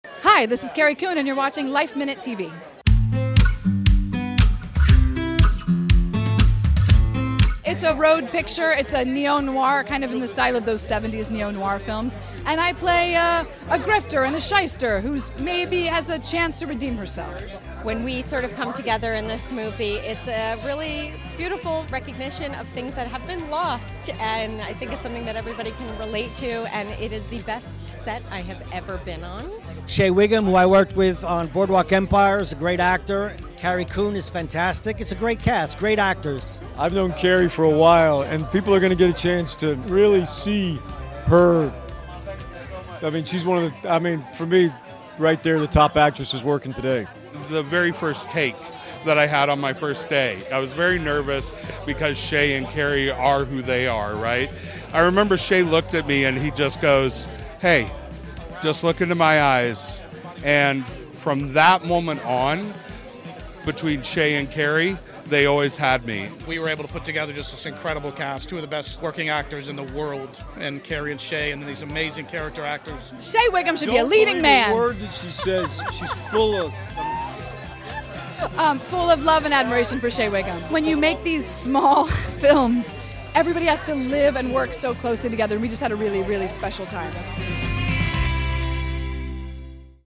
The cast and crew of Lake George joined us on the red carpet for the film’s world premiere Sunday at the SVA Theatre in New York City.